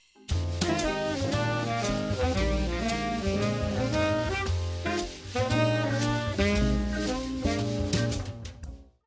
Below is a phrase I discovered while jamming yesterday.
lick-in-one-key-b-flat.mp3